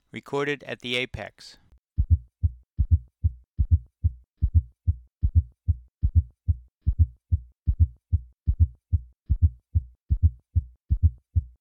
Heart Sounds
A fourth heart sound, S4, may be heard in otherwise healthy older patients.
S4 is heard immediately before S1. It is very soft and very low-pitched, and you must listen for it.